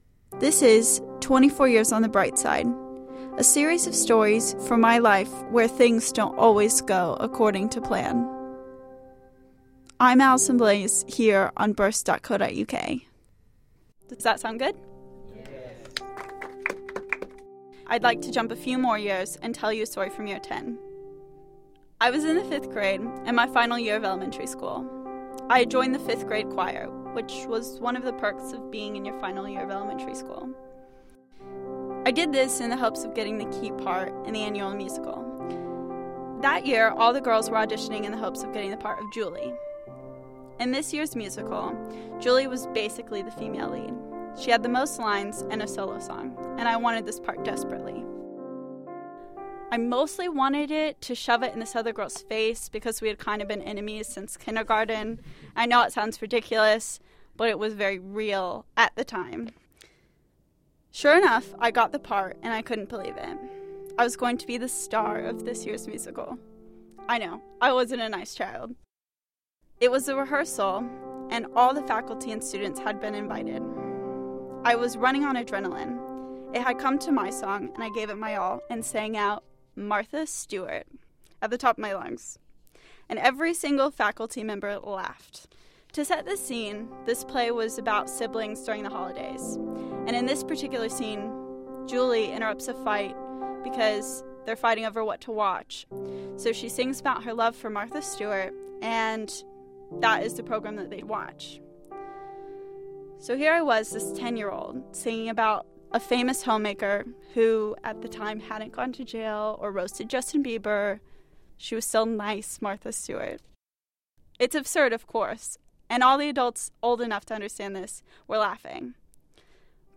’24 Years On The Brightside’ was recorded in front of a live audience.